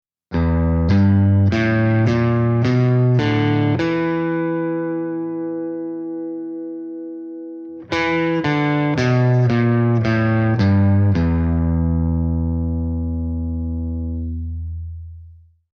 På figuren under kan du høre en bluesskala, og hvordan den kan brukes i andre stilarter enn der den har sitt opphav.
Bluesskalaen på gitar
I både sang og spill på gitar har man mulighet til å «bende» tonene ved å skyve strengene opp eller ned.